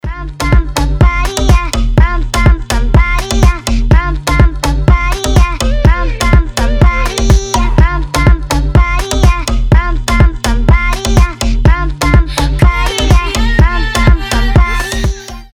• Качество: 320, Stereo
ритмичные
dance
Moombahton
забавный голос
electro house